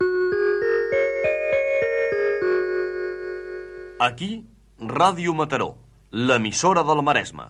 Indicatiude de l'emissora